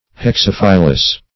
Search Result for " hexaphyllous" : The Collaborative International Dictionary of English v.0.48: Hexaphyllous \Hex*aph"yl*lous\, a. [Hexa- + Gr.